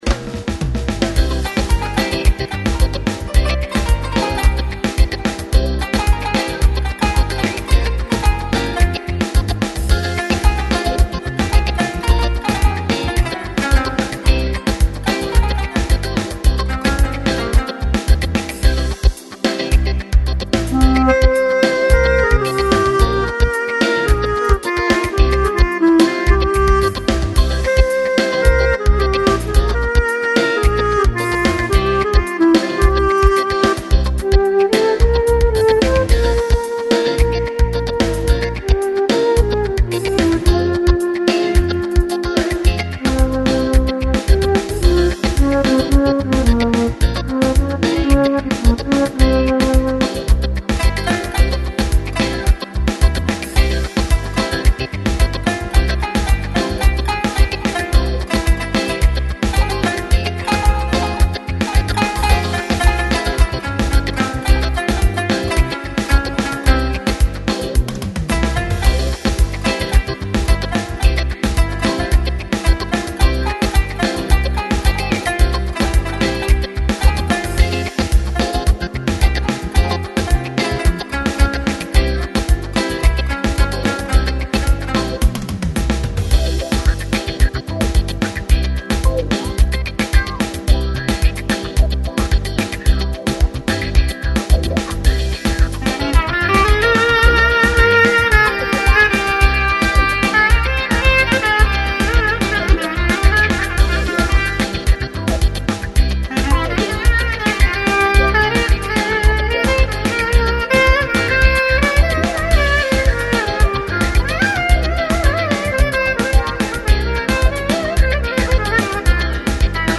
1970 li yıllarda bu tarzda çalardık. Bir veya iki gitar, davul ve bass gitar.....
ork-tarzi-oyun-havasi-mp3.6668